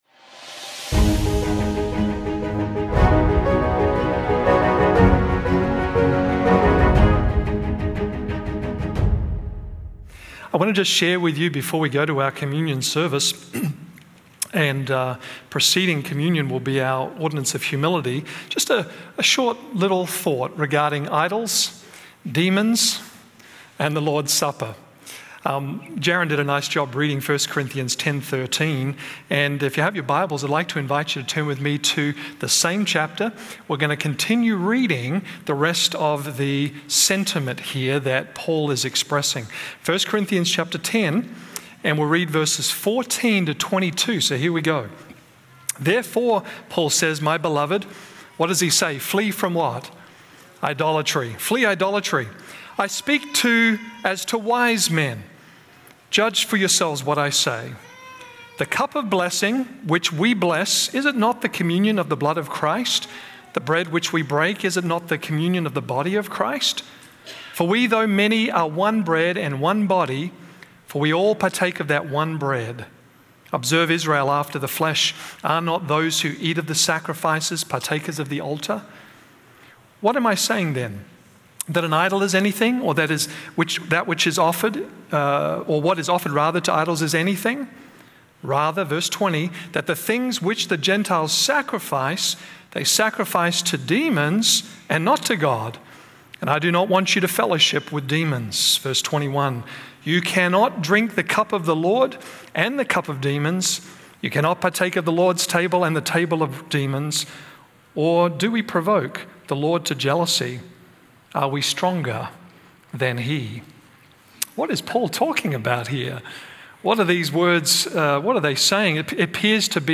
A message from the series "Central Sermons."
From Series: "Central Sermons"